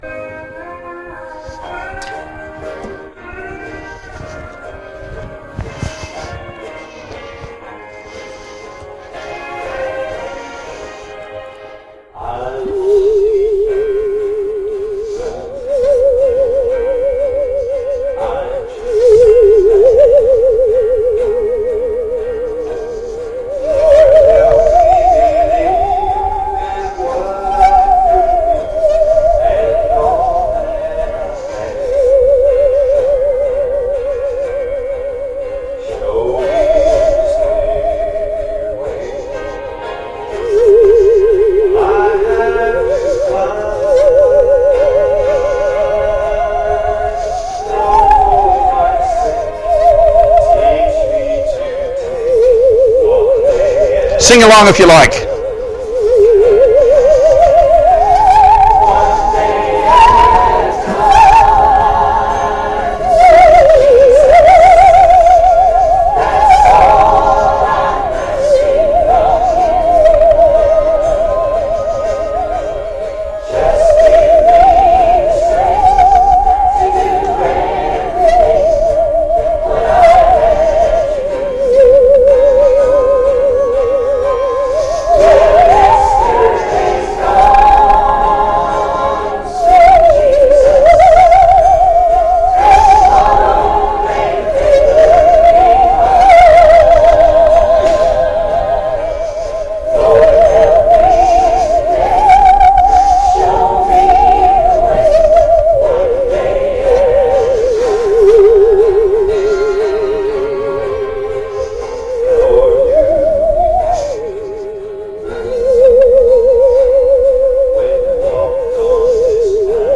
Special performances
played on the Saw